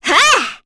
Cecilia-Vox_Attack3_kr.wav